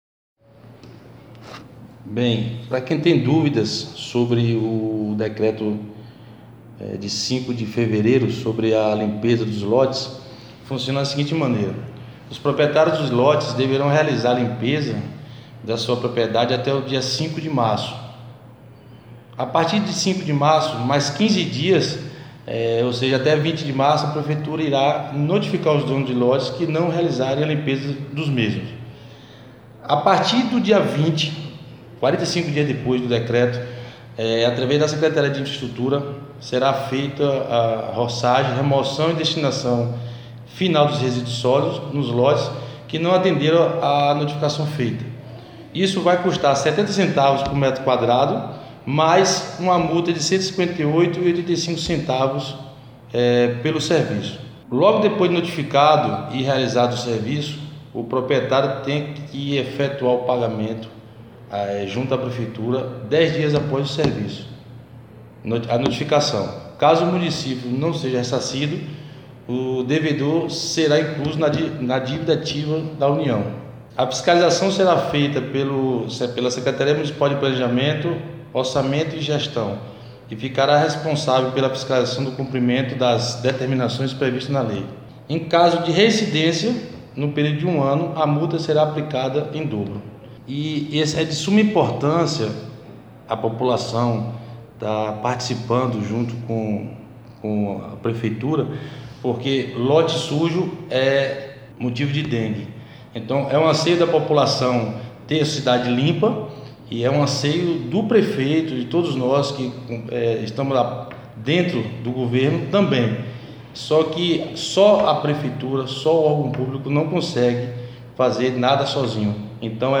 Acesse ao podcast e ouça aexplicações do Secretário de Infraestrutura, Franklin Willer.